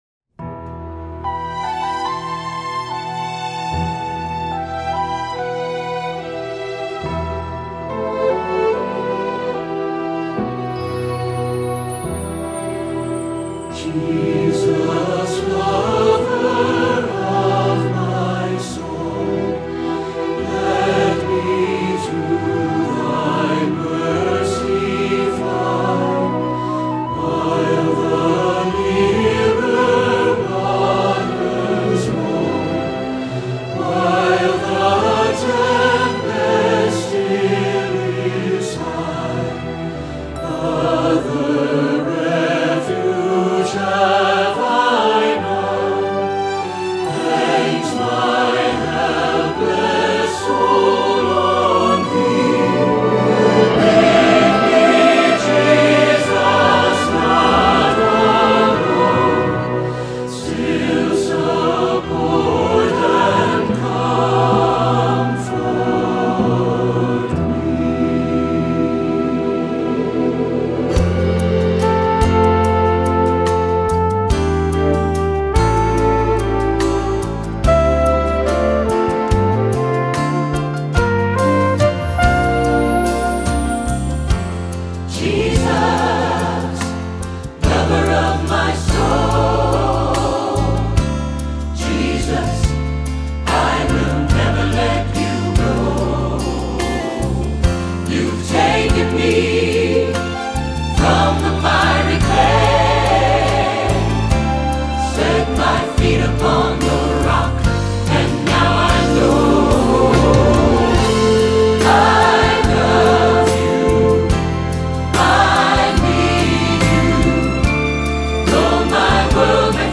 Voicing: Instrumental Parts